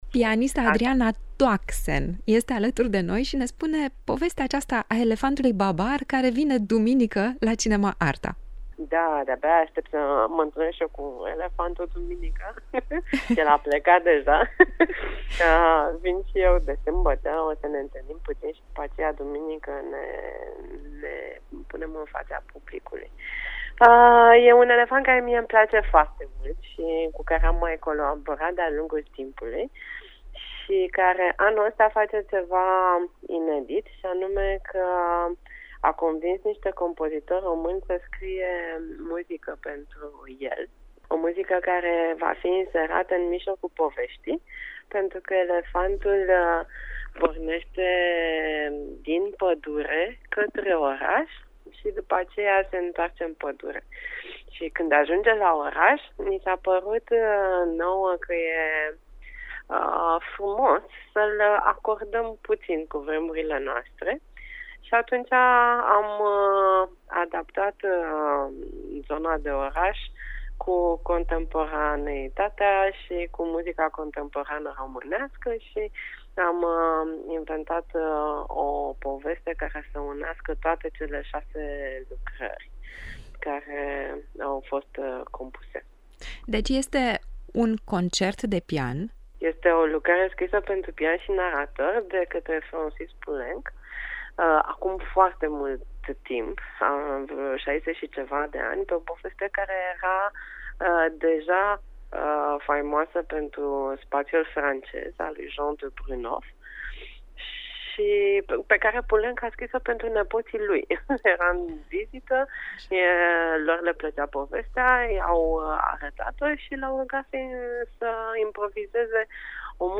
a acordat un interviu pentru Radio Cluj